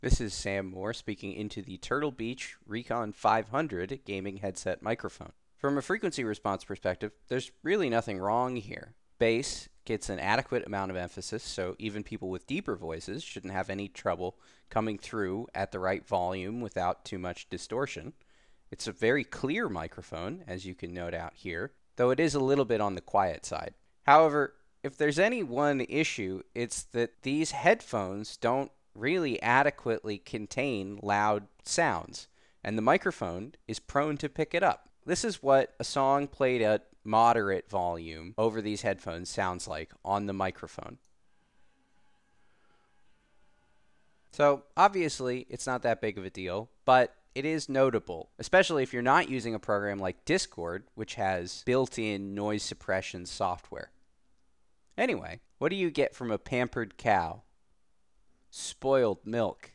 Turtle-Beach-Recon-500-mic-sample.mp3